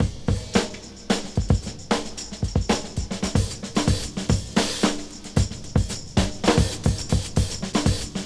• 116 Bpm Fresh Breakbeat Sample G Key.wav
Free breakbeat sample - kick tuned to the G note. Loudest frequency: 1681Hz
116-bpm-fresh-breakbeat-sample-g-key-yCa.wav